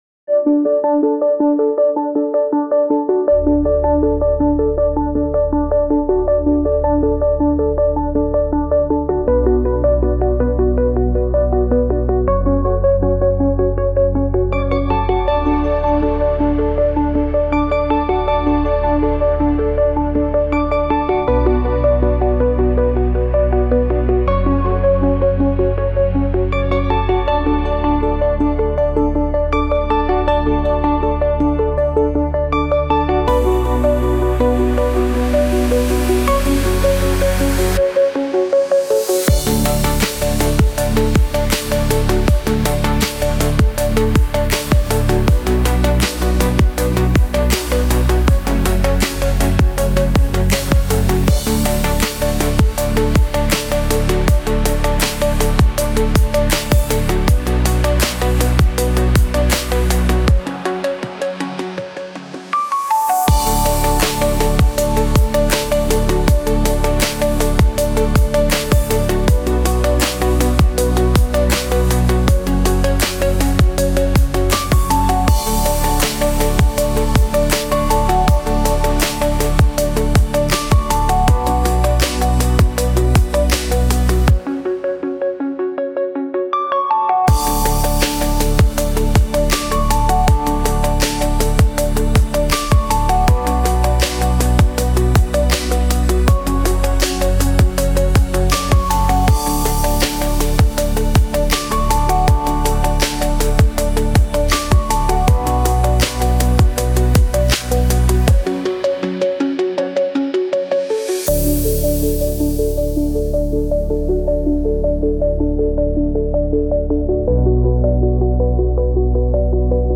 Inspirational and motivational instrumental track.
Genres: Background Music
Tempo: 79 bpm